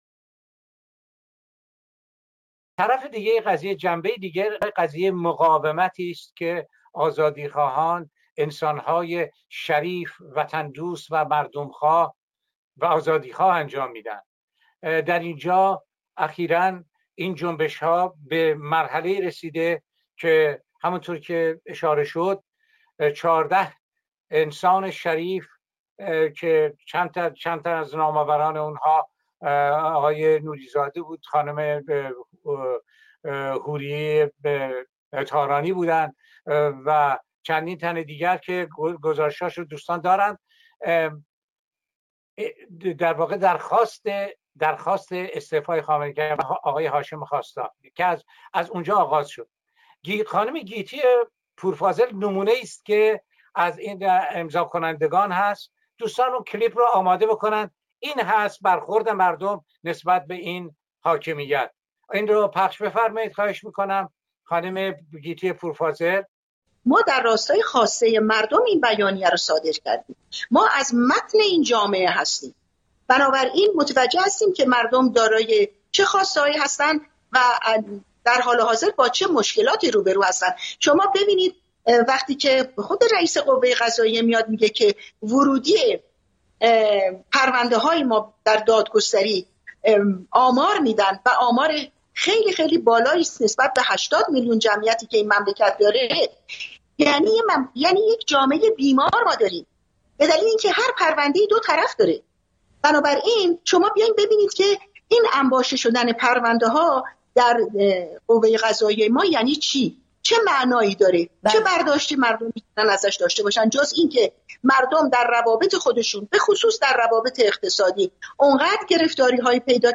بهرام مشیری، مورخ و پژوهشگر برجسته، به عنوان مهمان در نشست سراسری گذار در روز یک‌شنبه ۲۵ آگوست ۲۰۱۹ (۳ شهریورماه ۱۳۹۸) شرکت کرد که صحبت‌های او در چند بخش منتشر شد.
به گزارش گذار (سامانه دموکراسی و داد)، وبینار سراسری گذار از استبداد اسلامی به دموکراسی، با موضوع راهکارهای میدانی برای گذار خشونت پرهیز از استبداد اسلامی به دموکراسی، در جهت حمایت و شرکت فعال ایرانیان خارج از کشور از جنبش اعتراضی برای تغییرات بنیادی در حاکمیت سیاسی، با حضور جمعی از کنشگران مدنی، صاحب‌نظران و فعالان سیاسی، شامگاه روز یک‌شنبه ۲۵ آگوست ۲۰۱۹ (۳ شهریورماه ۱۳۹۸) برگزار شد.